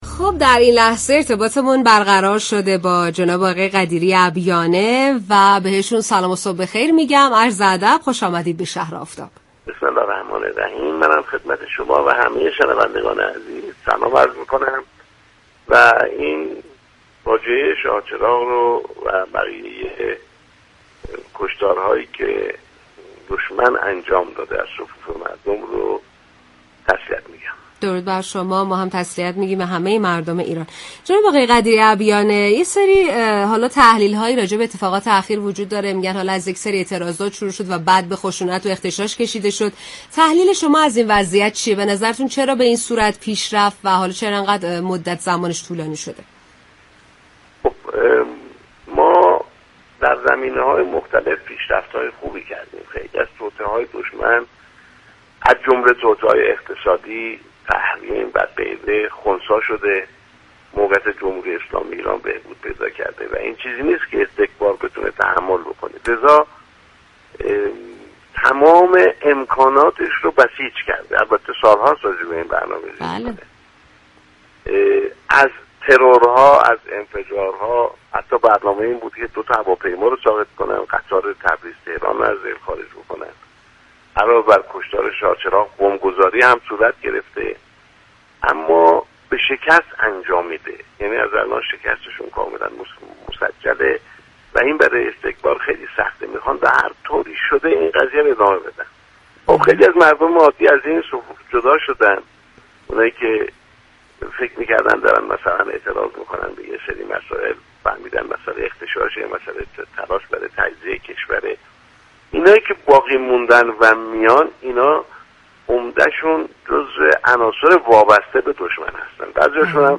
دریافت فایل پیشرفت ایران برای استكبار قابل تحمل نیست به گزارش پایگاه اطلاع رسانی رادیو تهران؛ محمدحسن قدیری ابیانه سفیر سابق ایران در استرالیا و مكزیك در گفت و گو با شهر آفتاب رادیو تهران درخصوص اعتراضات اخیر در كشور اینگونه اظهار داشت: موقعیت جمهوری اسلامی ایران بهبود پیدا كرده است و این پیشرفت‌ها برای استكبار قابل تحمل نیست؛ لذا تمام امكانات خود را برای ایجاد مشكلات این چنینی در كشورمان بسیج كرده است.